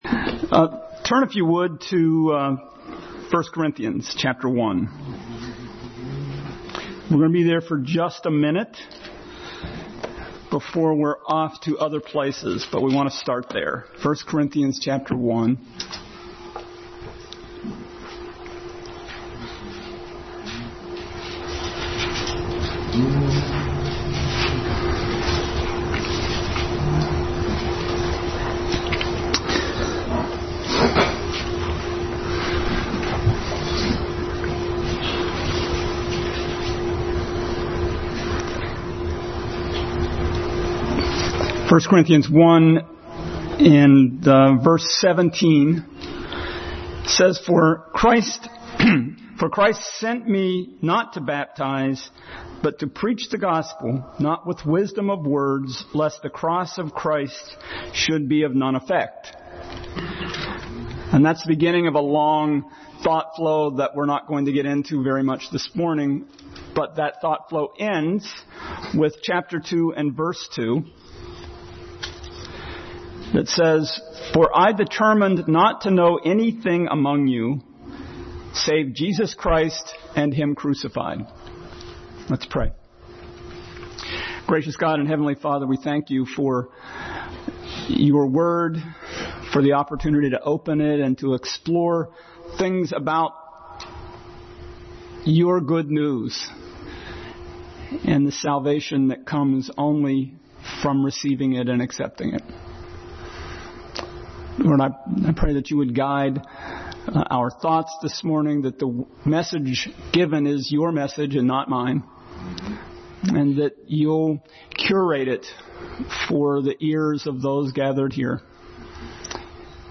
Passage: 1 Corinthians 1:17, 2:2, 15:3-9 Service Type: Sunday School